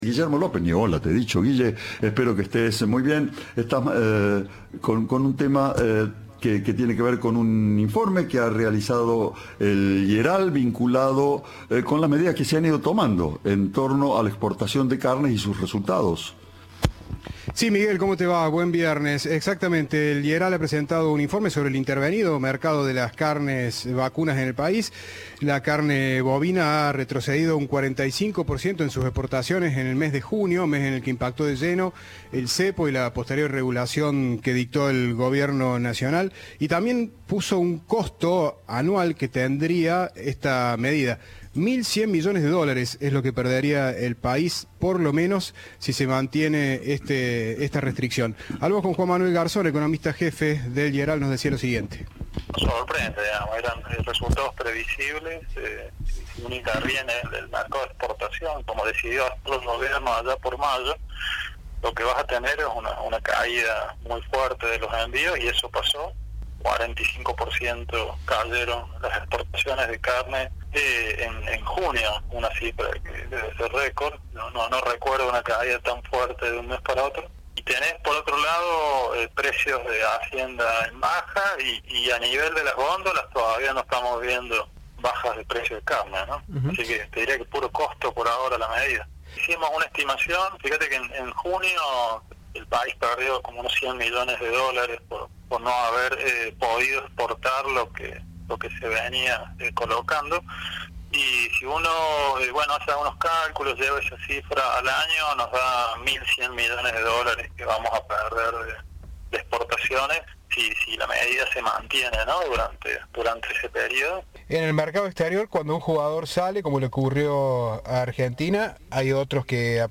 lamentó el impacto de la medida en diálogo con Cadena3.
Informe